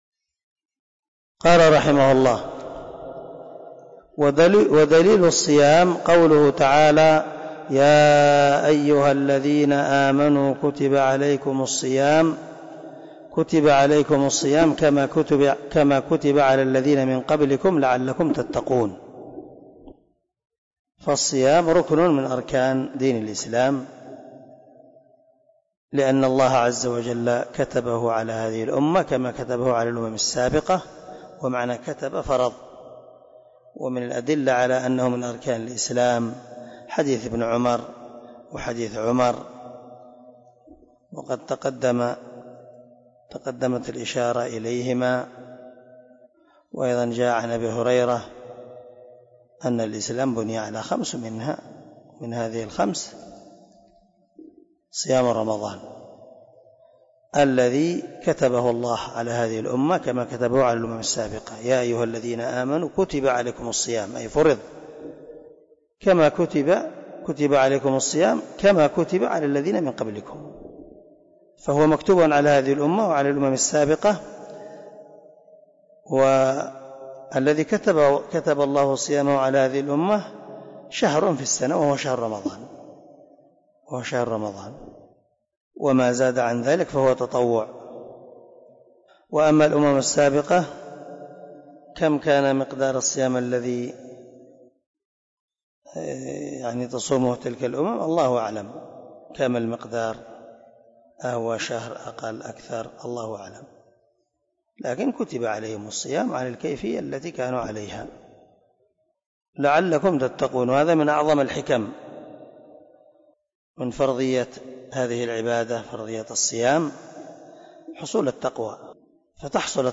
🔊 الدرس 26 من شرح الأصول الثلاثة
الدرس-26-ودليل-الصيام.mp3